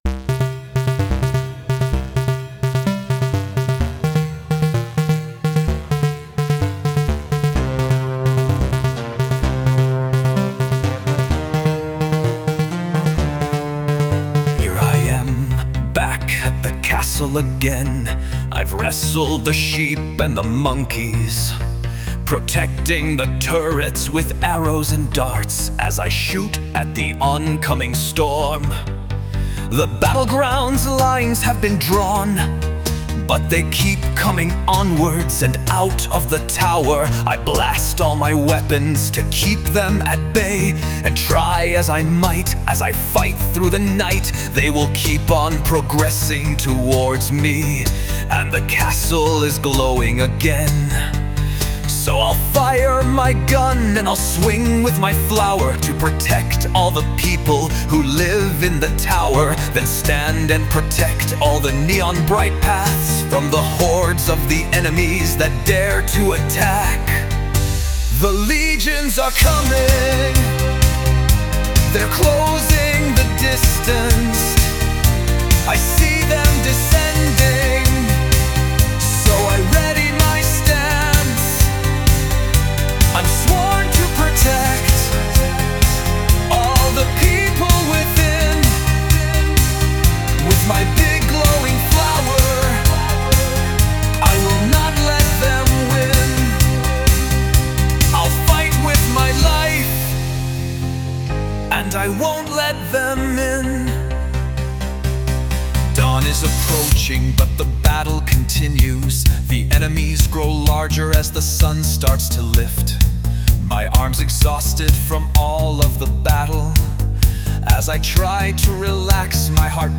Sung by Suno